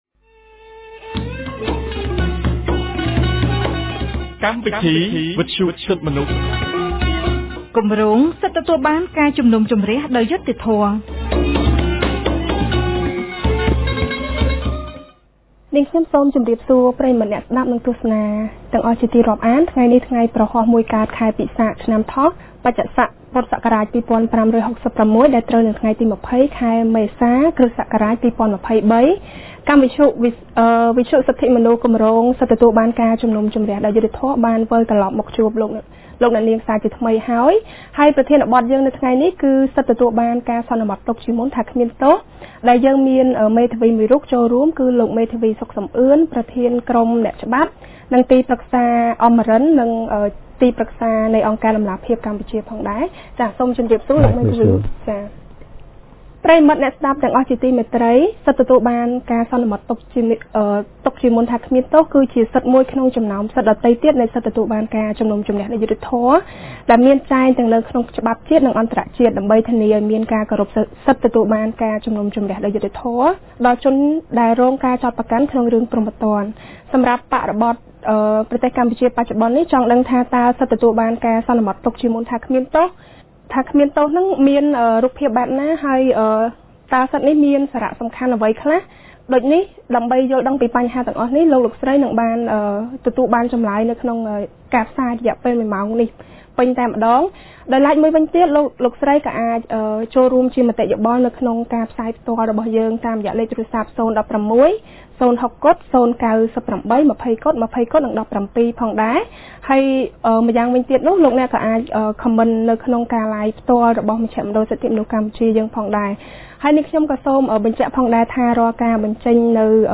On Thursday 20 April 2023, CCHR’s Fair Trial Rights Project (FTRP) of CCHR held a radio program with a topic on the Presumption of innocence and right to remain silent.